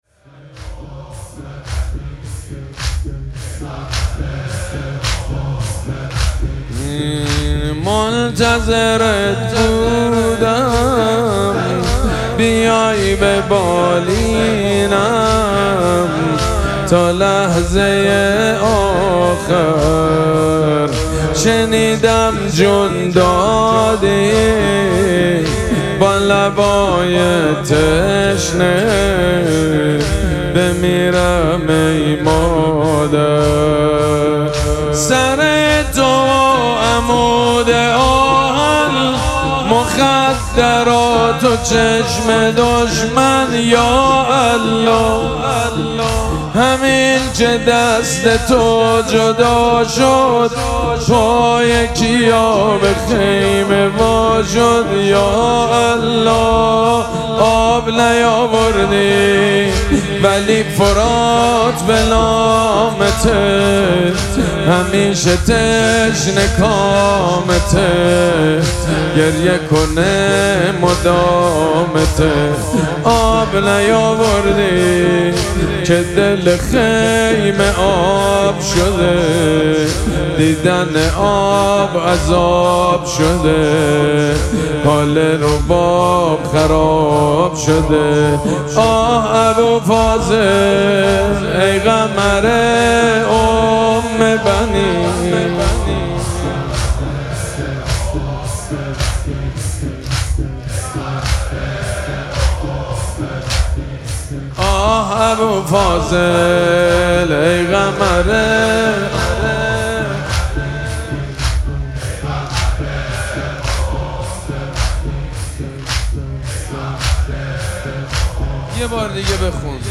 مراسم عزاداری وفات حضرت ام‌البنین سلام‌الله‌علیها
مداح
حاج سید مجید بنی فاطمه